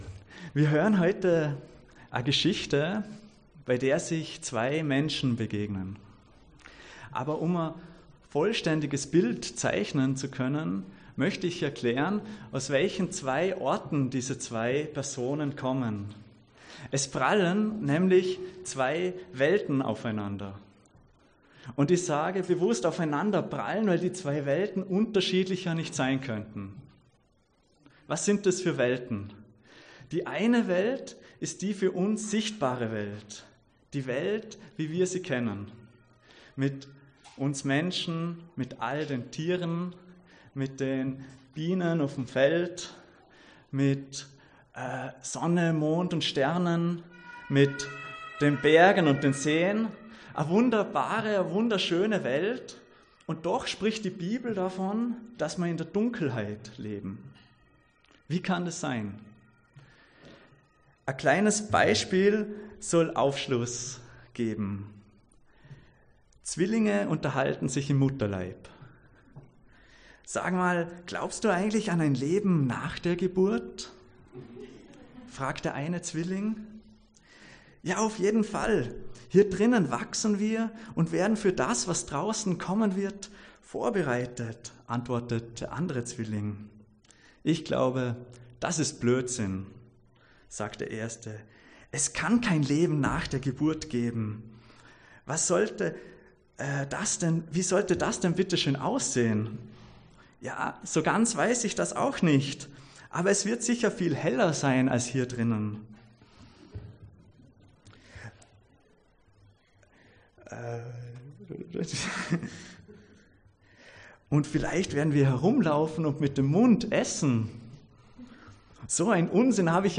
Gastpredigt